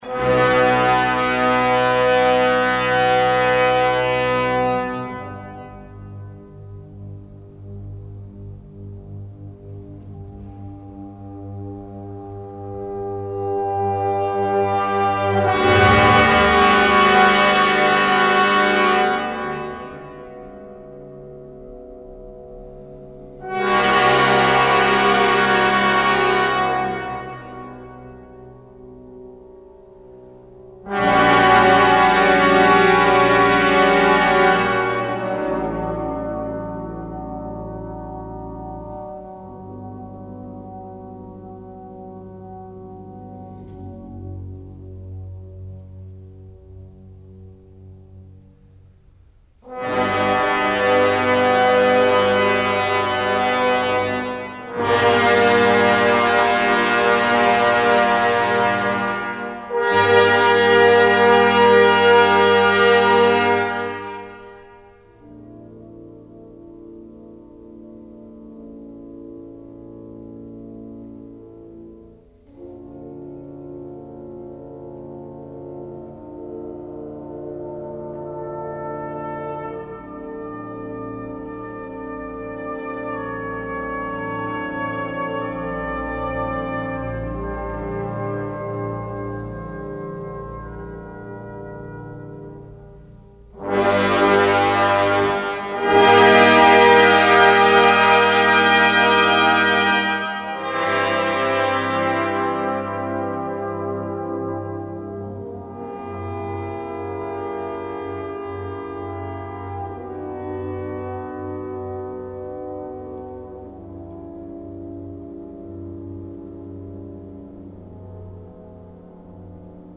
1. Fantasmi di un remoto passato: devozioni, speranze, martirio ... Lunghi accordi, lenti, da cima a fondo
Catacombe (orchestrazione di Ravel)